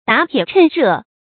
打鐵趁熱 注音： ㄉㄚˇ ㄊㄧㄝ ˇ ㄔㄣˋ ㄖㄜˋ 讀音讀法： 意思解釋： 比喻做事要抓緊時機，加速進行。